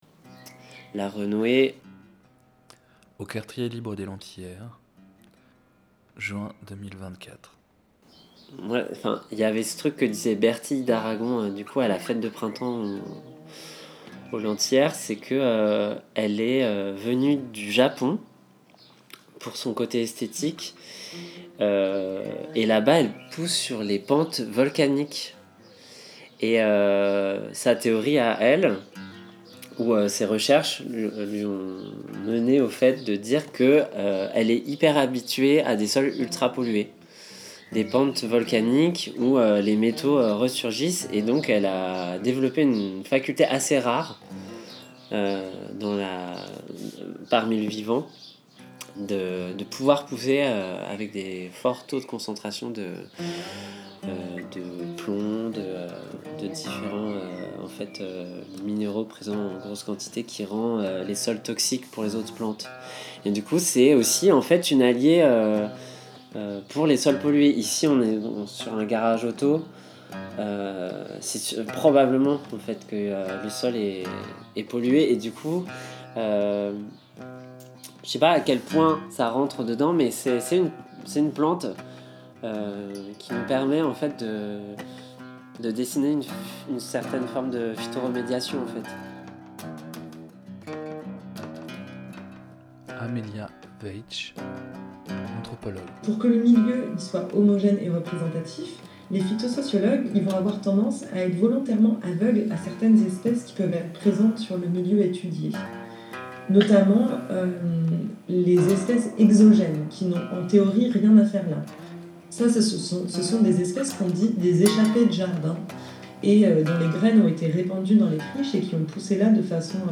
Un entretien avec un habitante des Lentillères